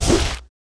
swing1.wav